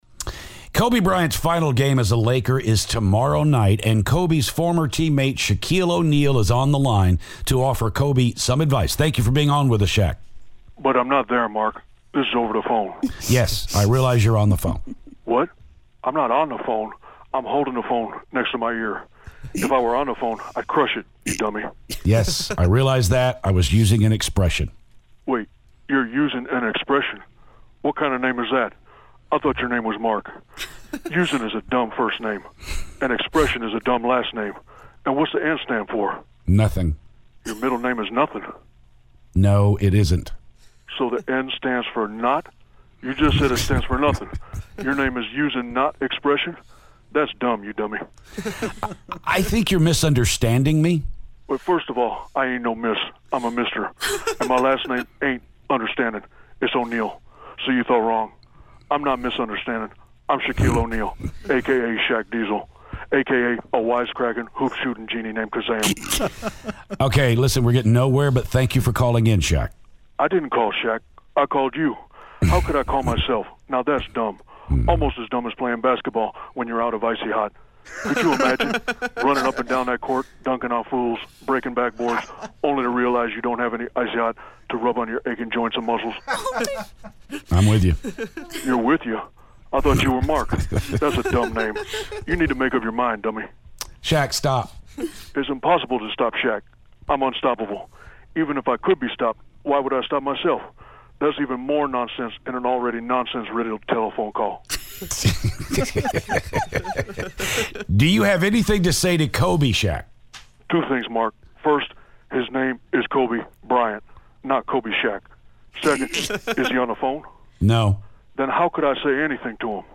Shaquille O'Neal Phoner
Shaq calls to talk about Kobe's retirement.